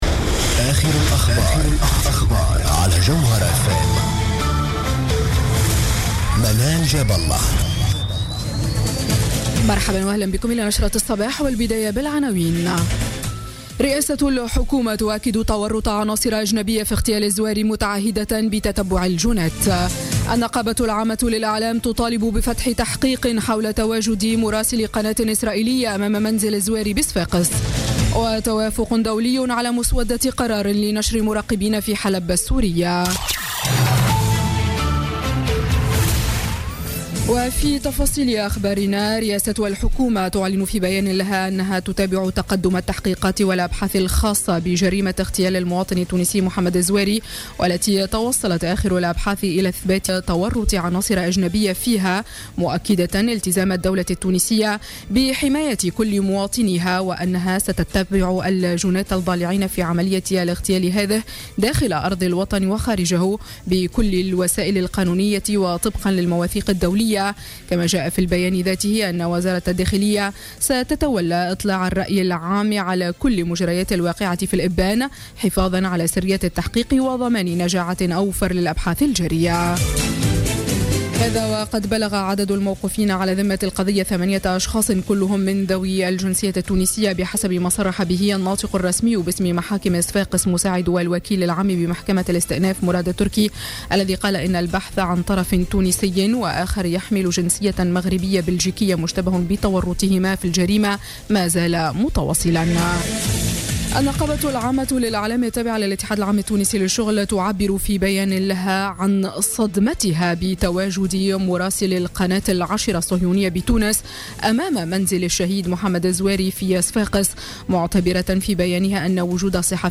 نشرة أخبار السابعة صباحا ليوم الاثنين 19 ديسمبر 2016